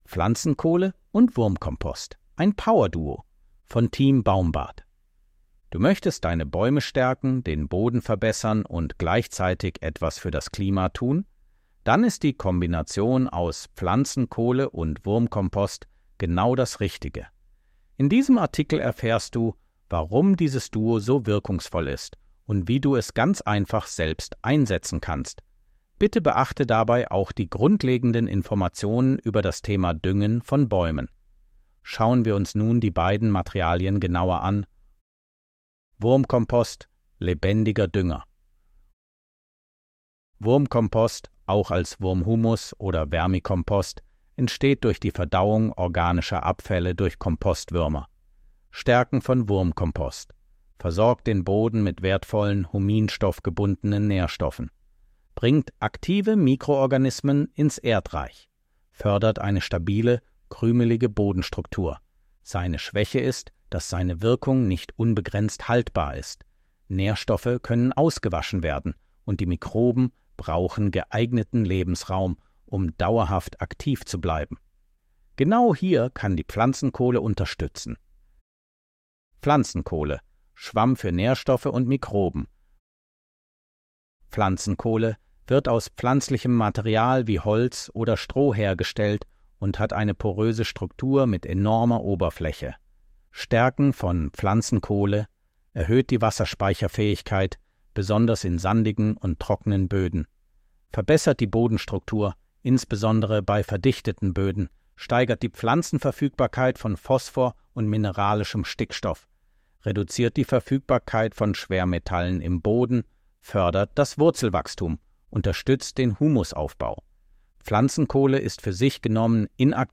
von Team baumbad 26.02.2026 Artikel vorlesen Artikel vorlesen Du möchtest deine Bäume stärken, den Boden verbessern und gleichzeitig etwas für das Klima tun?